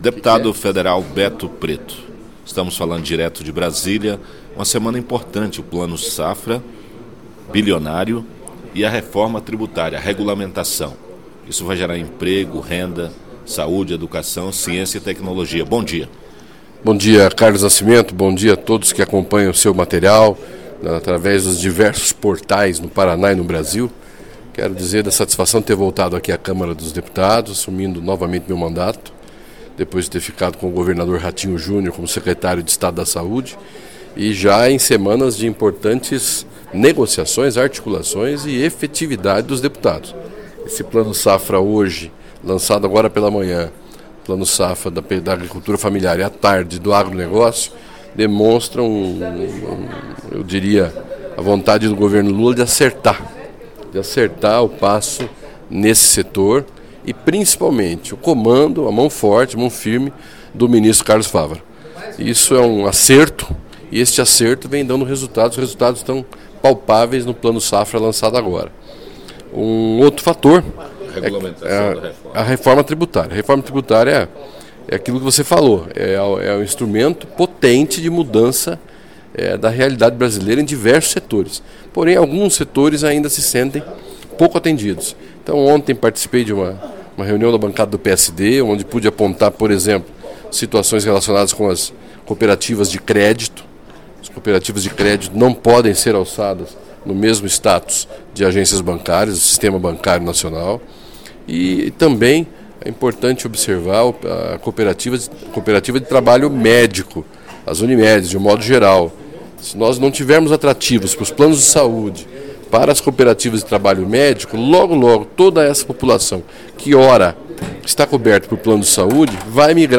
Ele detalhou sua visão sobre as pautas.